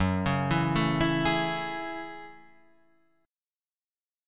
F#m9 Chord